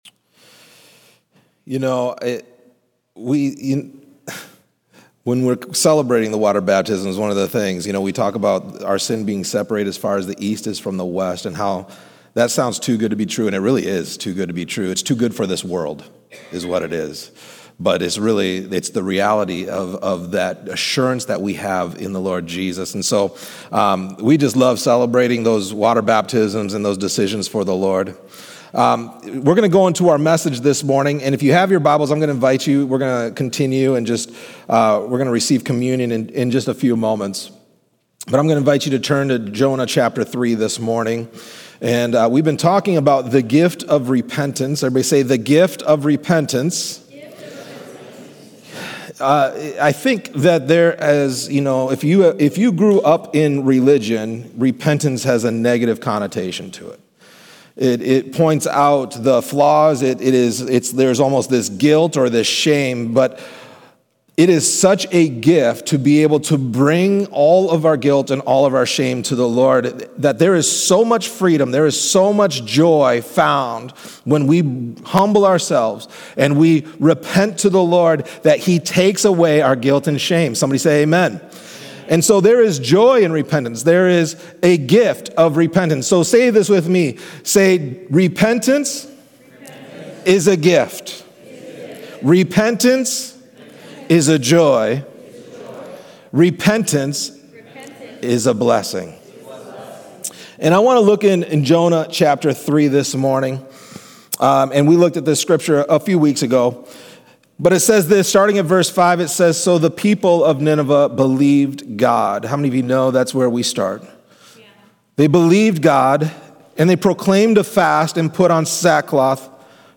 This sermon answers: 1. Why is repentance the doorway to freedom and renewal? 2. How can we experience ongoing transformation through repentance? 3. What does it mean to live a lifestyle that continually turns toward God?